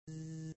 3_buzz.mp3